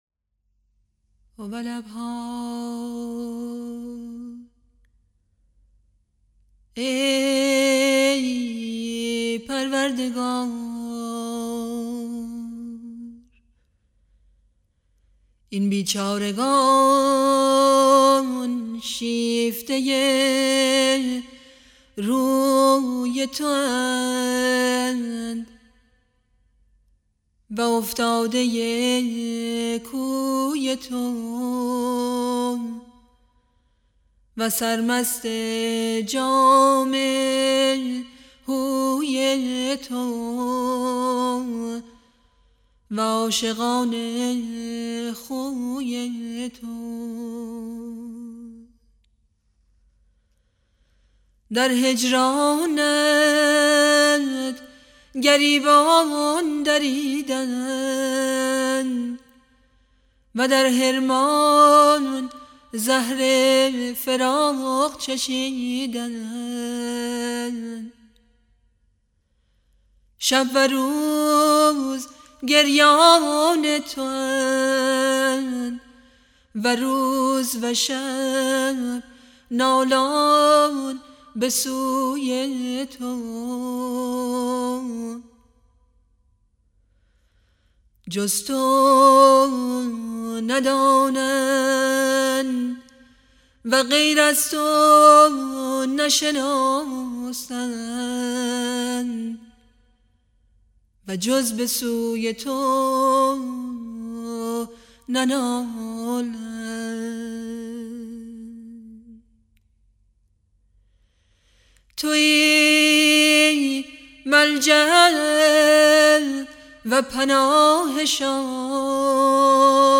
مناجات های صوتی فارسی